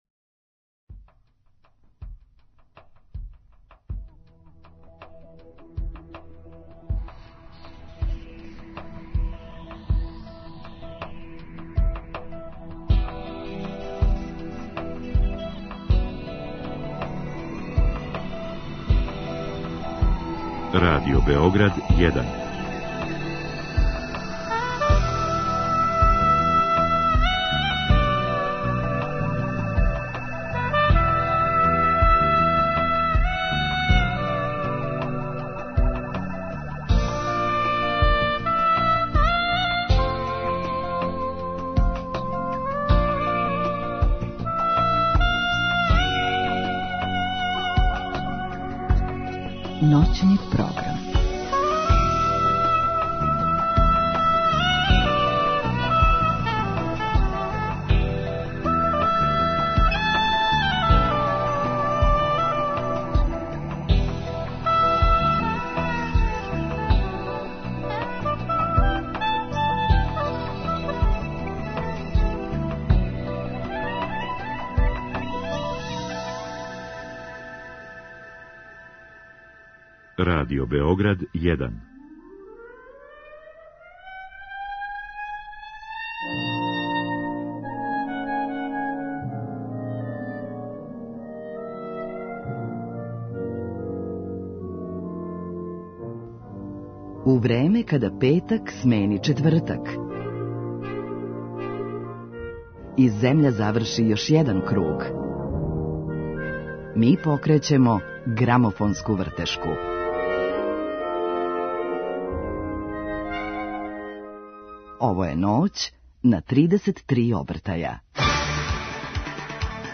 У Ноћном програму имаћемо прилике са чујемо композиције са овог албума, сазнамо како су настајале и откуд идеја да се појаве у новом руху.